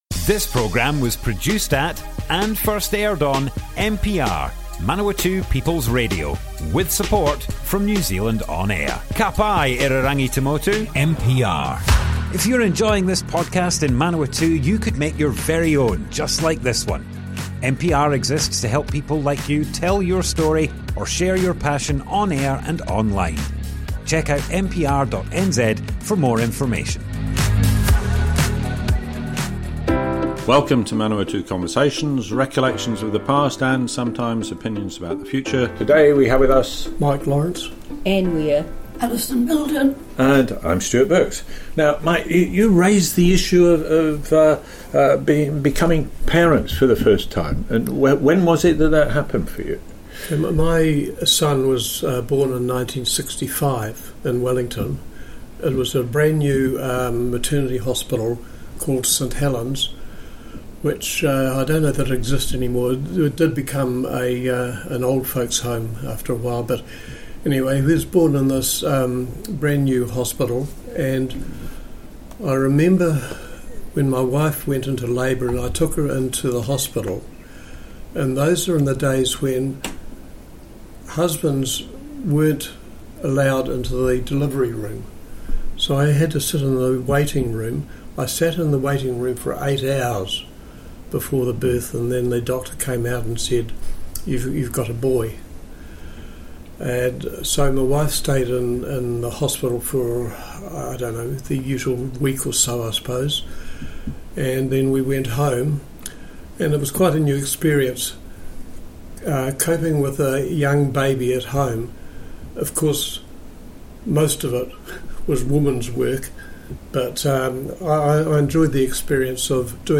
Group discussion, part 1. Experience with our first children - Manawatu Conversations